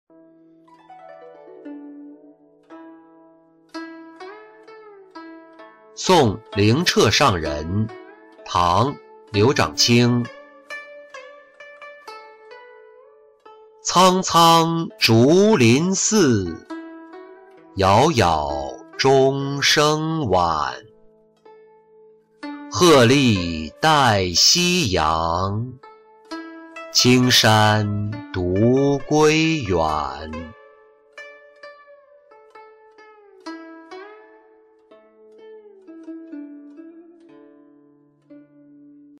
送灵澈上人-音频朗读